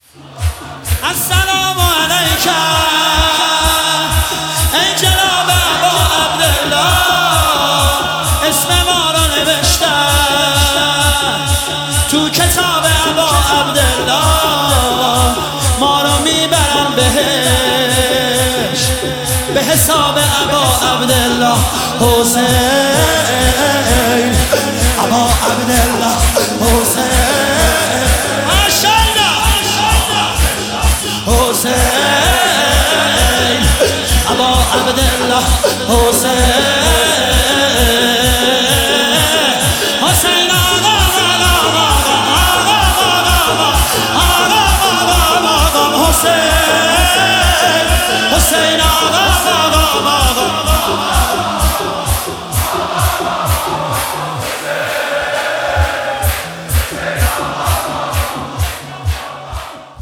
مداحی شور
شهادت حضرت ام البنین (س) 1403